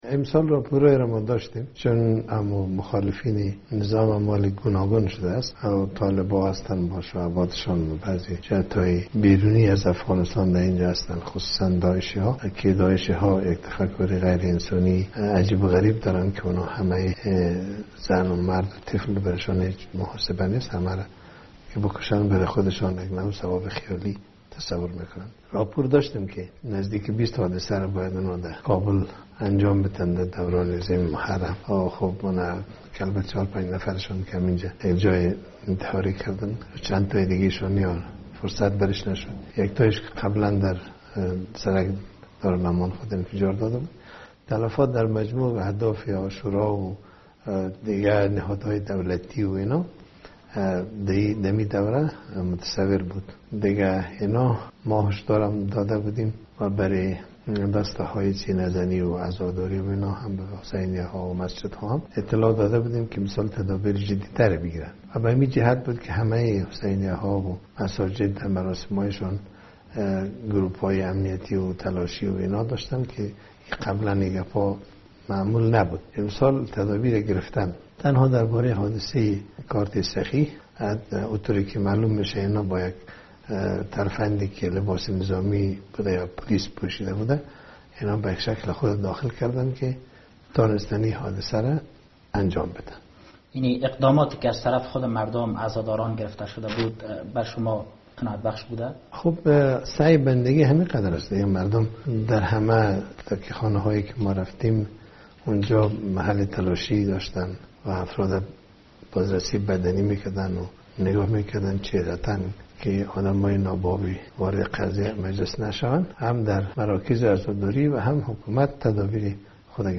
مصاحبه - صدا
آقای محقق در گفتگو با رادیو آزادی در کابل گفتهاست، راه اندازی چنین حملهها کار طالبان نیست.